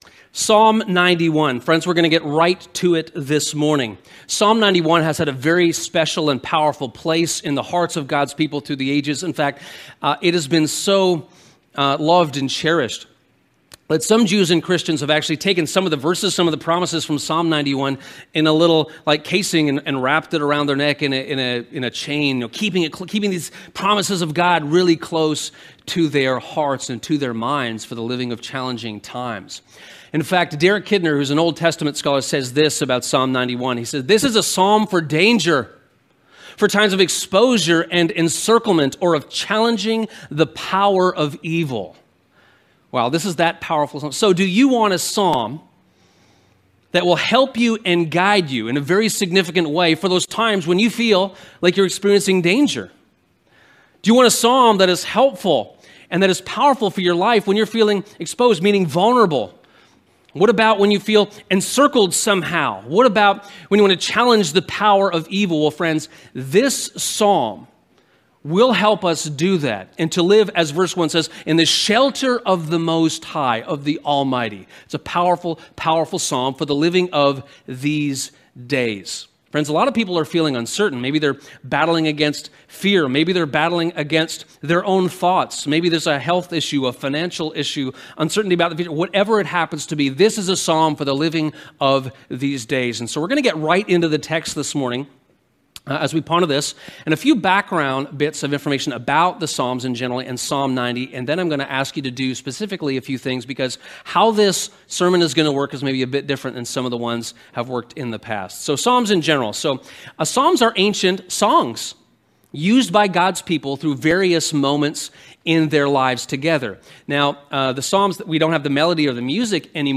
In the Shelter of the Most High [Sermon]